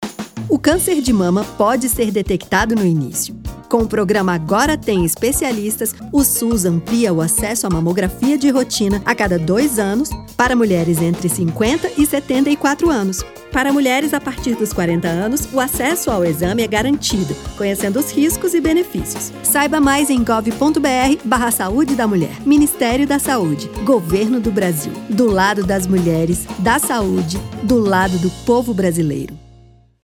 audio-spot-30s-campanha-outubro-rosa.mpeg